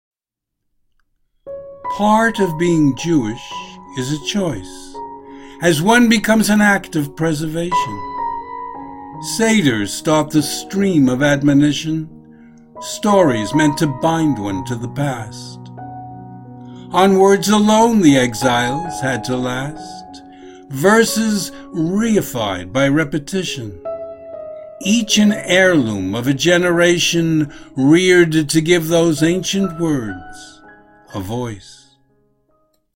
Music: Ancienne Melodie de la Synagogue.